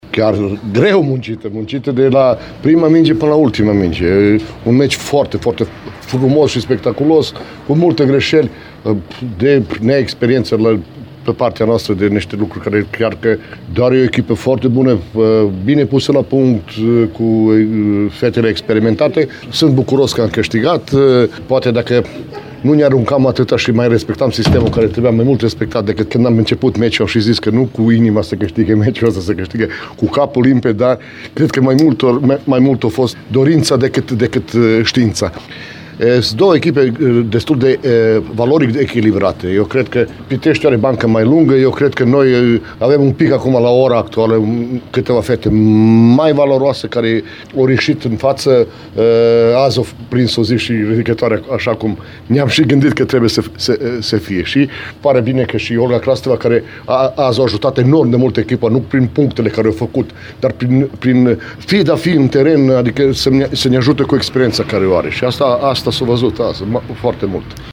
Declarații